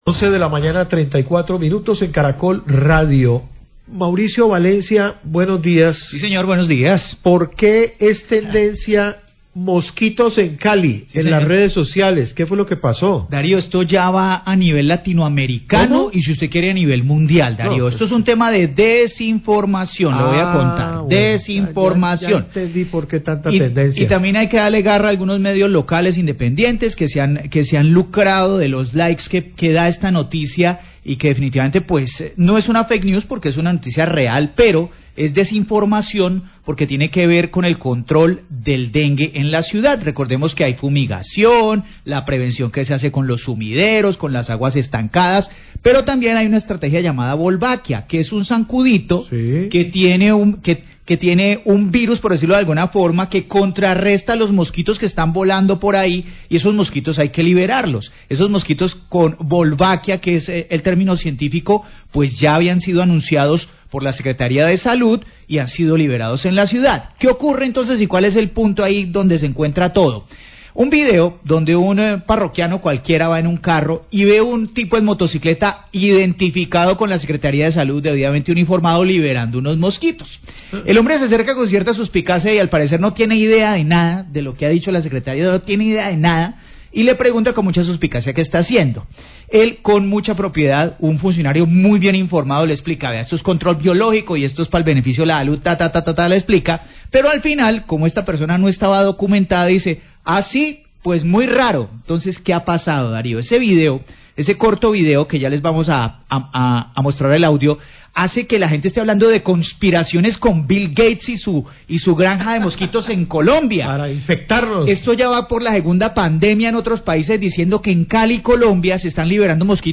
Periodista comenta sobre video de control biológico del dengue que se volvió viral por teorías conspirativas
Radio
Los periodistas comentan sobre un video en redes sociales que se voilvió viral cuando un conductor hace preguntas a un contratista de la Secretaría de Salud de Cali que realiza un procedimeinto de control biológico del dengue y que se volvió tendencia por teorías conspirativas.